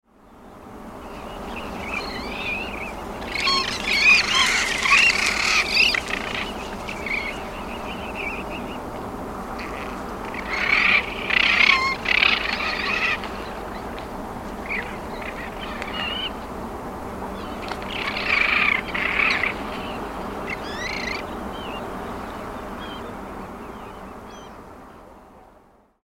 Fregata magnificens
Nome em Inglês: Magnificent Frigatebird